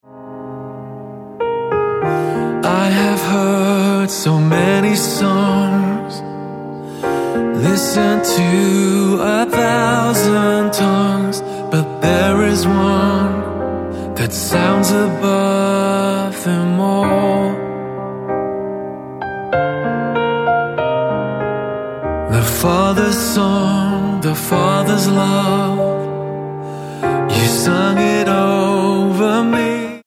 Eb